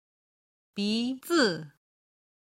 鼻子　(bí zi)　鼻
※軽声の「zi」は四声で表現しています。